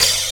35 OP HAT.wav